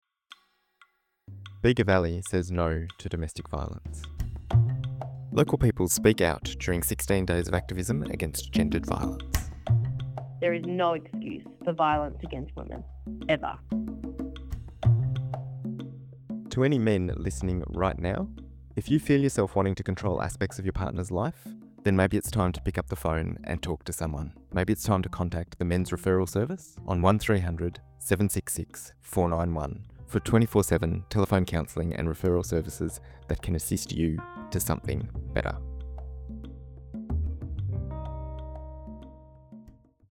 This year, the Bega Valley Domestic Violence & Sexual Assault Committee collected 25 statements from Bega Valley Community members, men and women from all walks of life, calling on all of us to do our part to stop Gender-Based Violence.
As part of this campaign, we collected brief audio statements from local Bega Valley community members to raise awareness about domestic, family, and sexual violence.